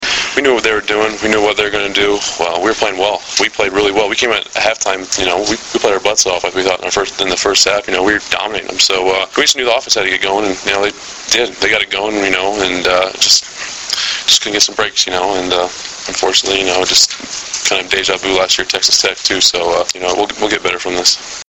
Press Conference Audio Links (Oct. 21)